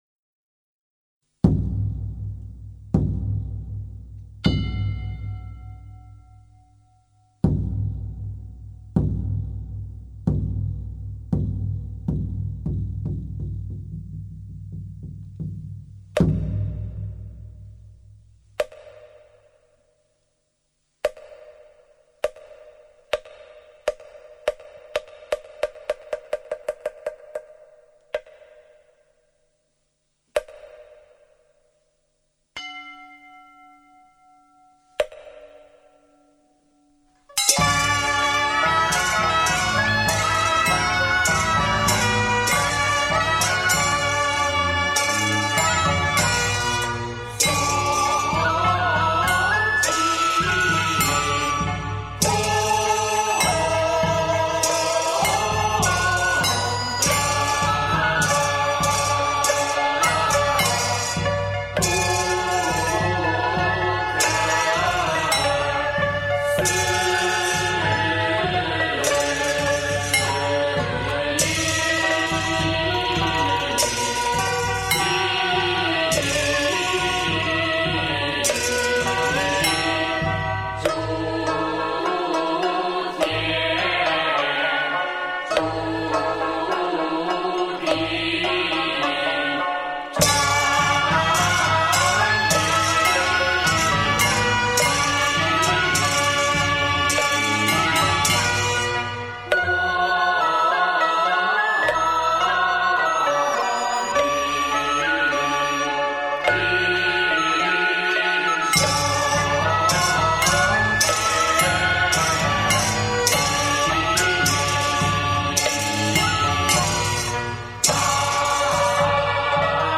选取道教仪式中的部分曲目，请上海名家演唱，充分表现出道家韵腔的况味。